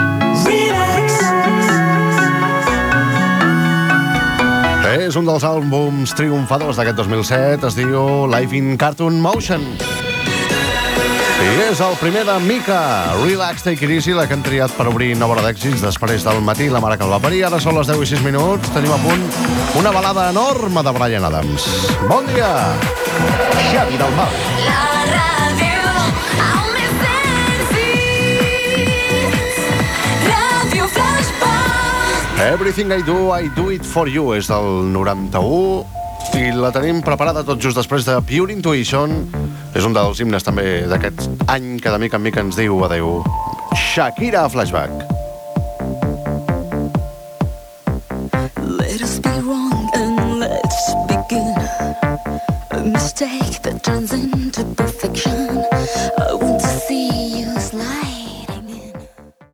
a1c2812ef511134ccc0d15a7b72469cb0c85a1b5.mp3 Títol Ràdio Flaixbac Emissora Ràdio Flaixbac Cadena Flaix Titularitat Privada nacional Descripció Tema musical, hora, indicatiu i presentació d'un tema musical. Gènere radiofònic Musical